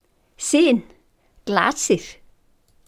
Listen to pronunciation: Syn Glasir,